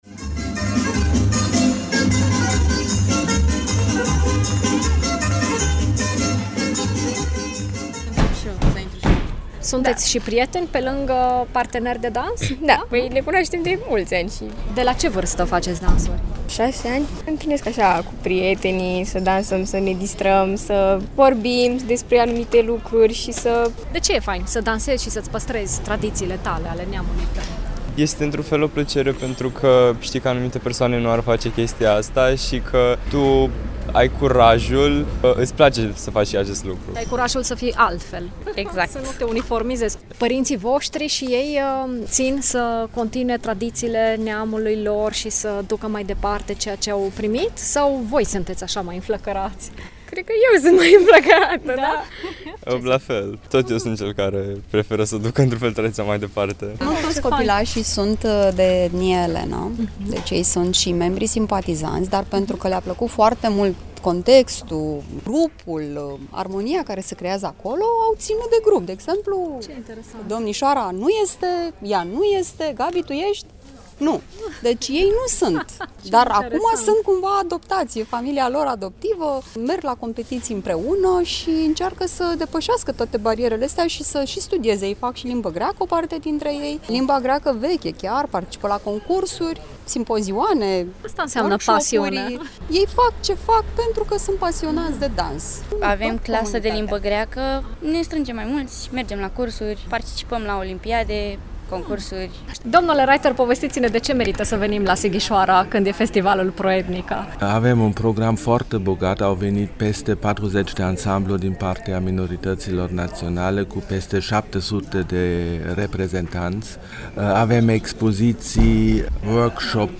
Peste 700 de reprezentanți ai celor 20 de minorități naționale au cântat, au dansat și au savurat împreună diversitatea, valorile tradiționale, finalul de vară și mai ales prietenia, în Cetatea Medievală din Sighișoara.